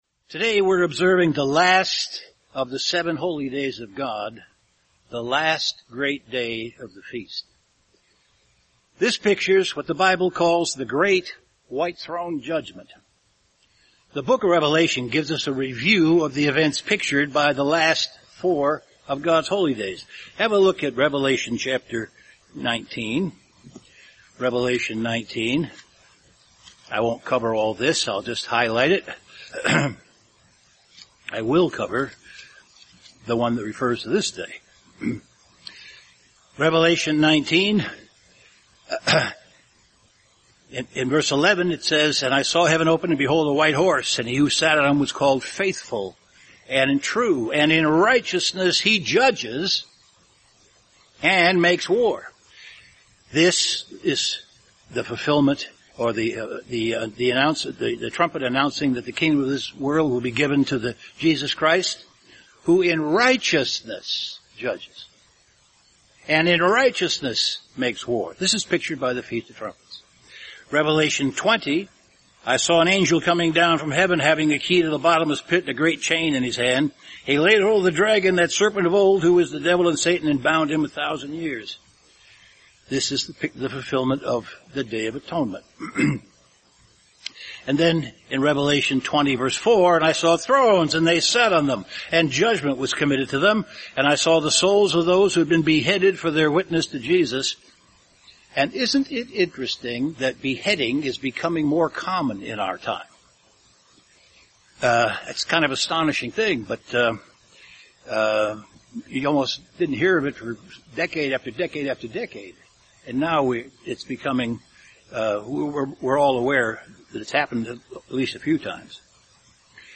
This sermon was given at the Wisconsin Dells, Wisconsin 2014 Feast site.